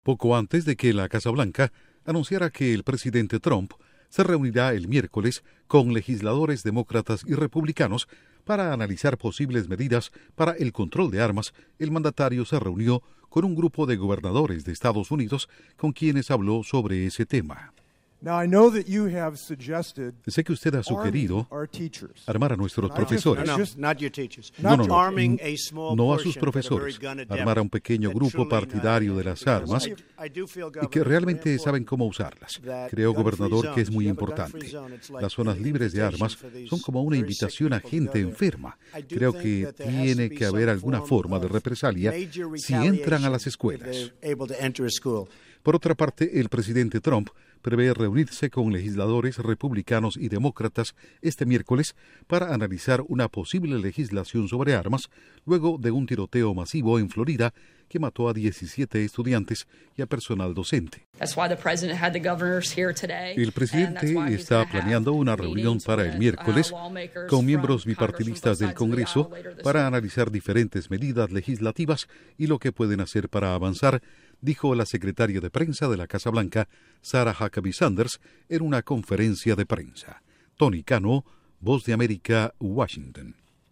Trump se reunirá con congresistas republicanos y demócratas para discutir una posible legislación sobre armas. El mandatario dialogó este lunes con gobernadores de EE.UU. en busca de soluciones. Informa desde la Voz de América en Washington